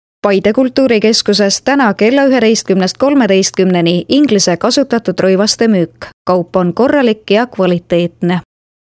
Sprechprobe: Industrie (Muttersprache):
Professionell voice over artist from Estonia.